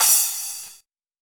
Drums/CYM_NOW! Cymbals